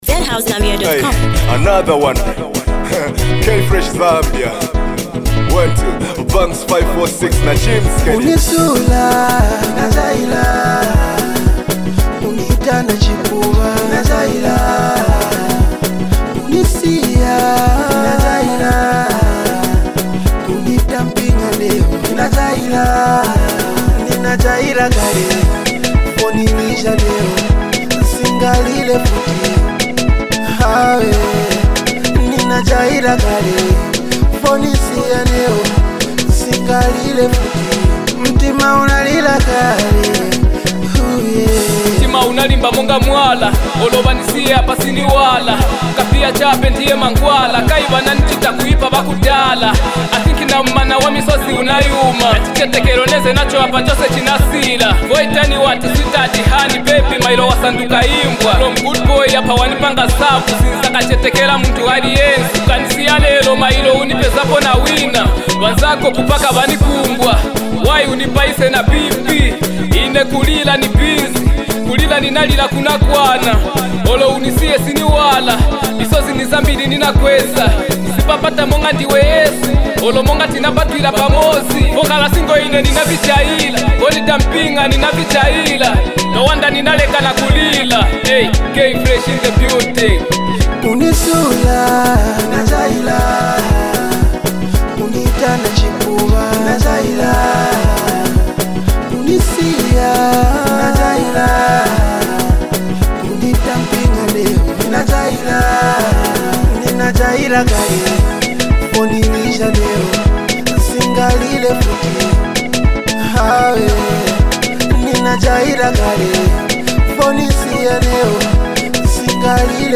raw anthem about resilience